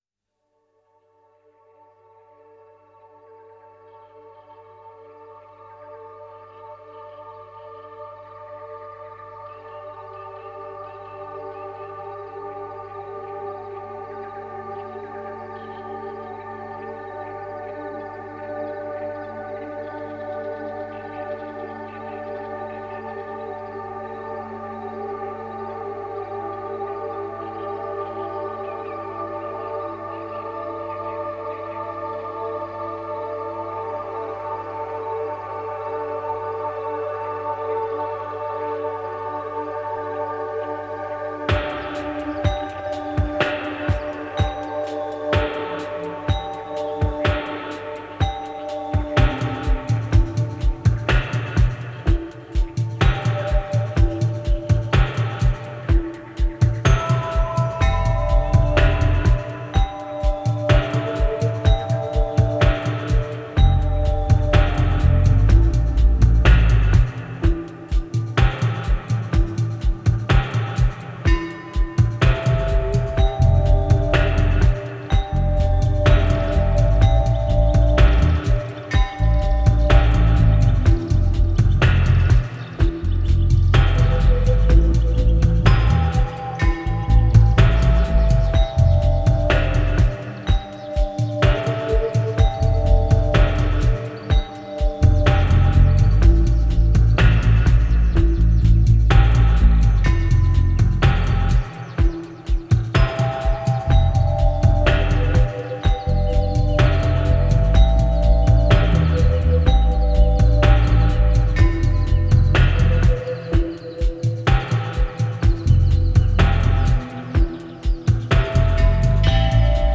Genre: Downtempo, Ambient.